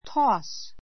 toss tɔ́ːs ト ー ス 動詞 ❶ ぽいと （軽く） 投げる; 放り上げる Ken tossed the ball to Ben.